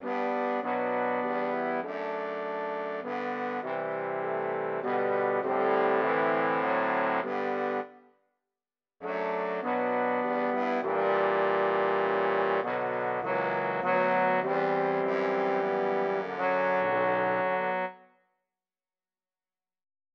Это оркестровое произведение, навеянное сборником поэм Виктора Гюго «Осенние листья» (1831).